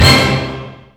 goal.mp3